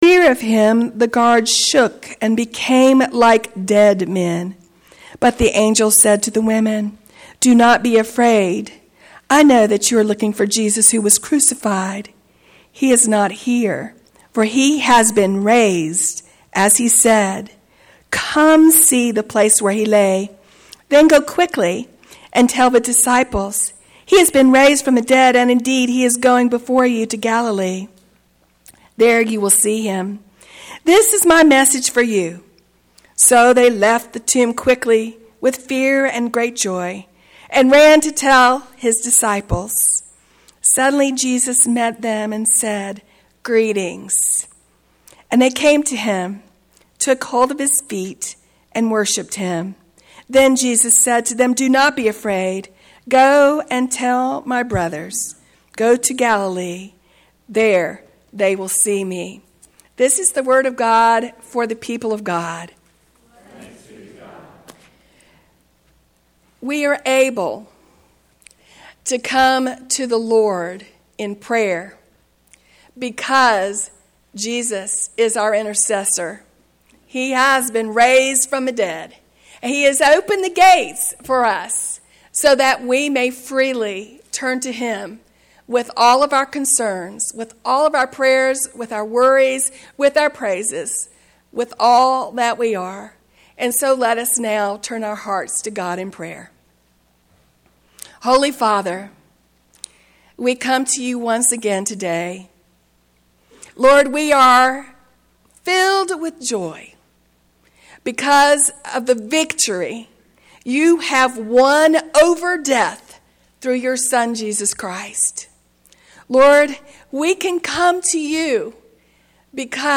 Chatsworth First Methodist Church Sermons